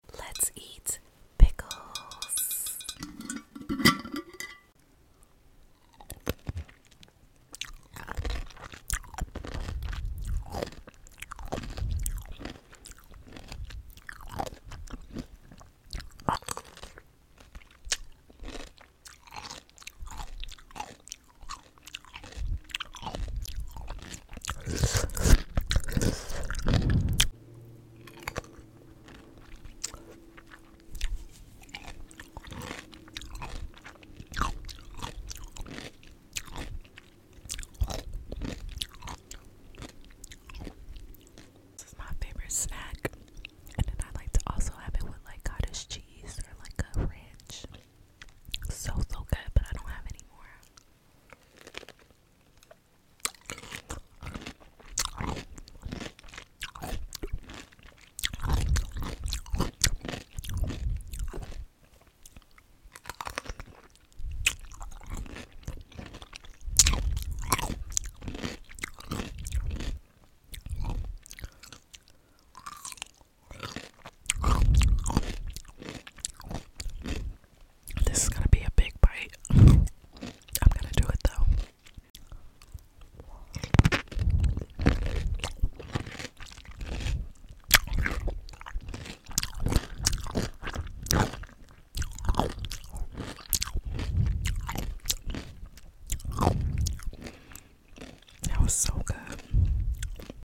Crunchy, juicy, and so satisfying… sound effects free download
Let’s enjoy some pickle eating ASMR! Close your eyes and listen to every bite🥒 #P#PickleASMRE#EatingSoundsC#CrunchyASMRF#FoodASMRA#ASMRCommunityM#MouthSoundsP#PickleMukbangS#SatisfyingSoundsR#RelaxingASMRASMRFoodie Crunchy, Juicy, And So Satisfying…